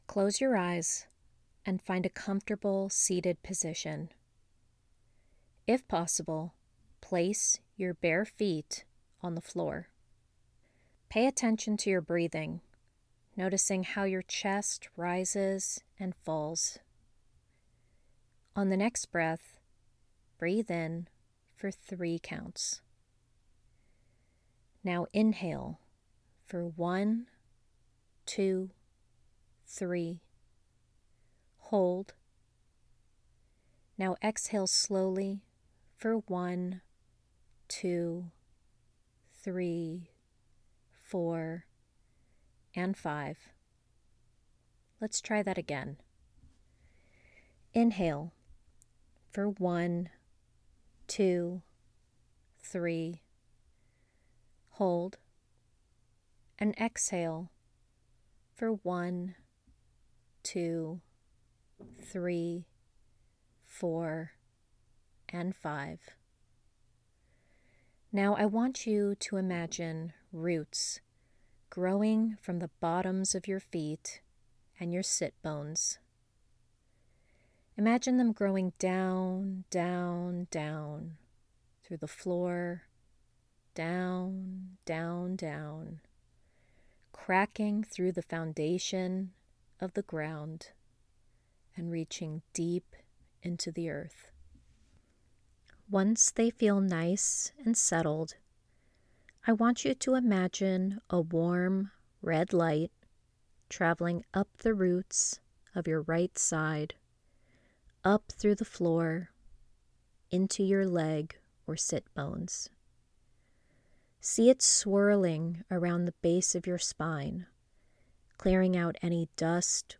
pre-spell-meditatioin